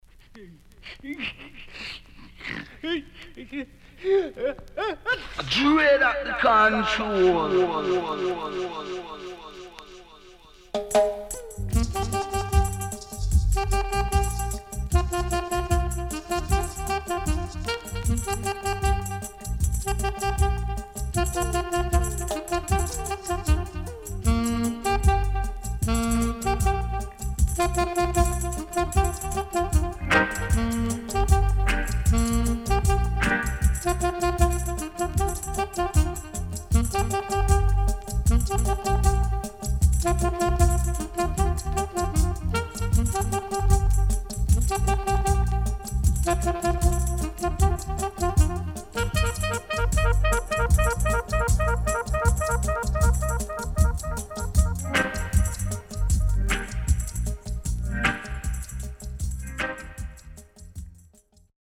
HOME > REGGAE / ROOTS  >  KILLER & DEEP  >  70’s DEEJAY
Killer Deejay & Dubwise.Good Condition
SIDE A:盤質は良好です。